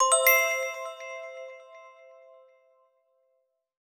Star Collect.wav